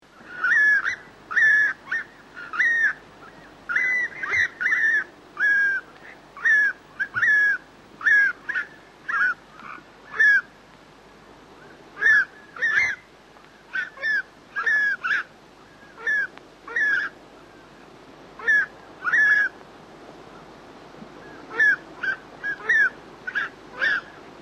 Pukeko calls
Pukeko1.mp3